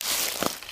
High Quality Footsteps
STEPS Bush, Walk 22.wav